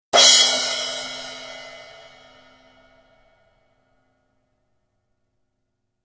ZILDJIAN ( ジルジャン ) >A ZILDJIAN SPLASH 12
素早く短いサステイン。